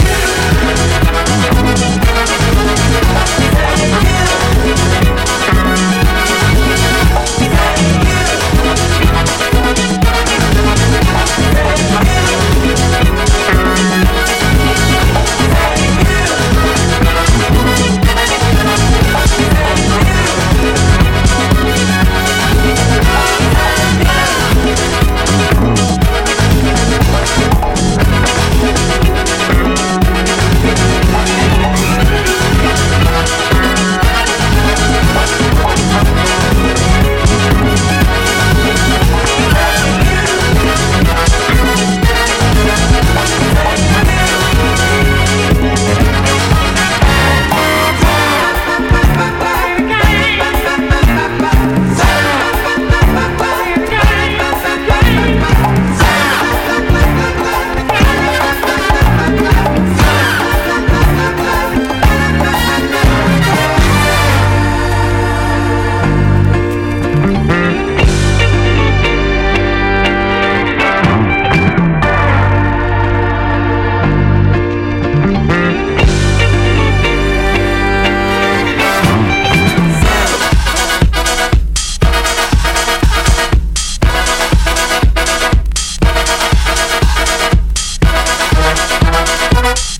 ナイスなパーティ感溢れるDJユースな一枚に仕上がっているので、現場でもバッチリ活躍してくれそうですね！